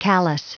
Prononciation du mot callus en anglais (fichier audio)
Prononciation du mot : callus